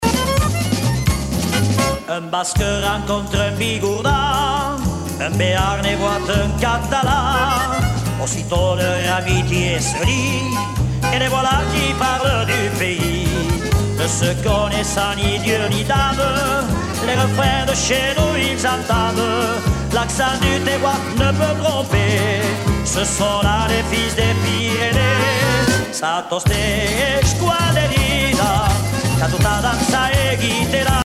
danse : fandango ;
Pièce musicale éditée